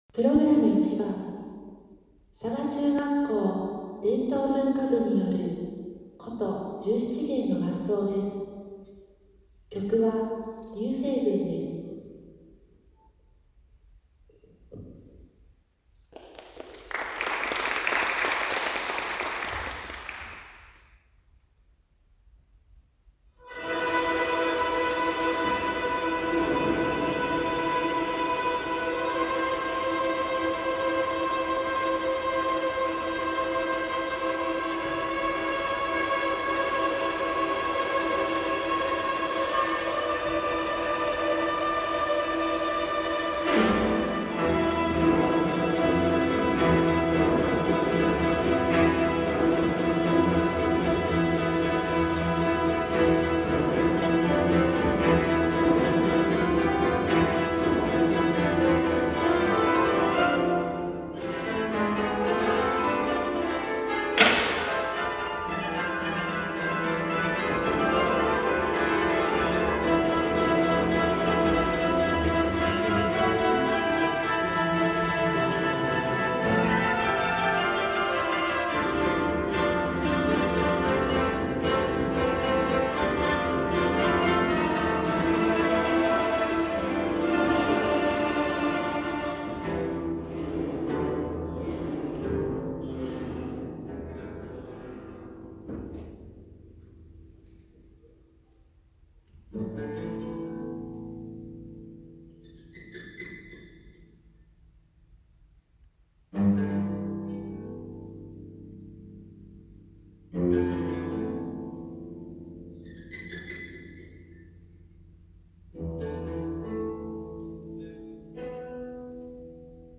第７３回京都市中学校生徒音楽会に出演しました 〜伝統文化部箏チーム〜
11月７日土曜日，京都堀川音楽高校のホールにて，第３６回京都市中学校総合文化祭・第７３回京都市中学校生徒音楽会が開催され，嵯峨中学校伝統文化部筝チームが参加しました。
→「龍星群」〜箏演奏〜